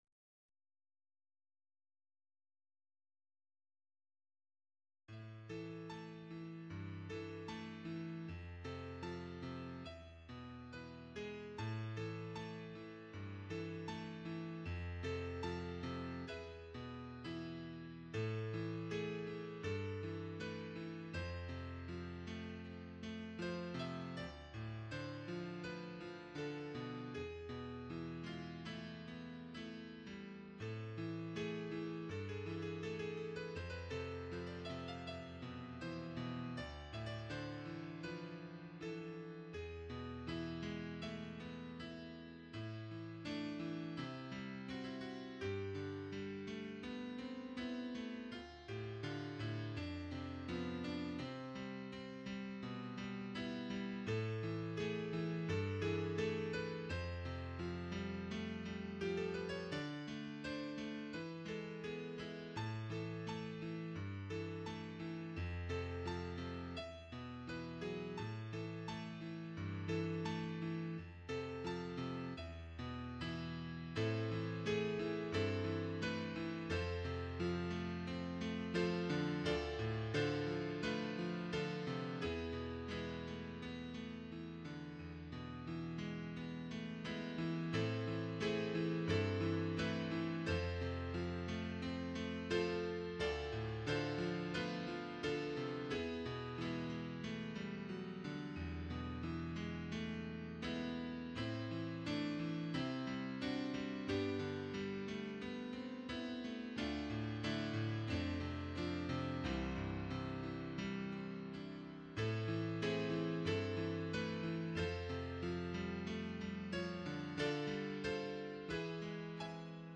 Música para misa